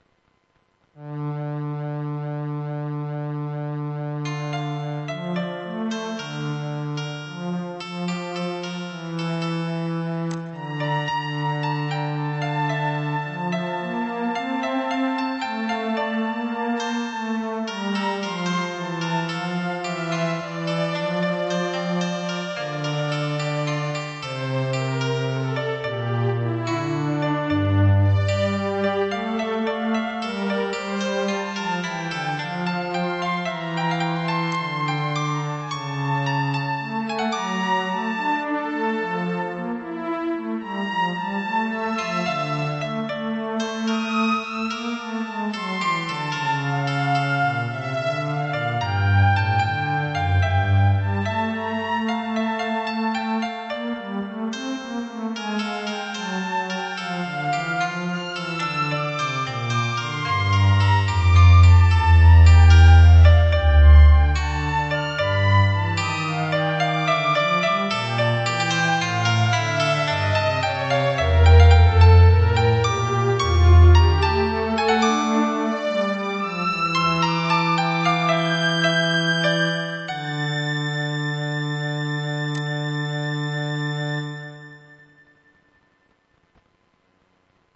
a meditative song in medieval style